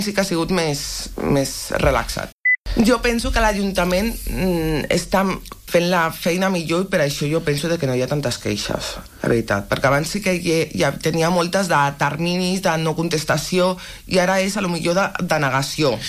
L’any d’activitat de la Sindicatura Municipal va de juliol a juliol, i a l’espera del balanç oficial de l’últim i en seu plenària, Natàlia Costa n’ha donat detalls en una entrevista a l’FM i + de Ràdio Calella TV.